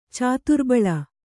♪ cāturbaḷa